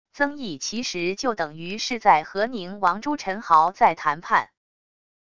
曾毅其实就等于是在和宁王朱宸濠在谈判wav音频生成系统WAV Audio Player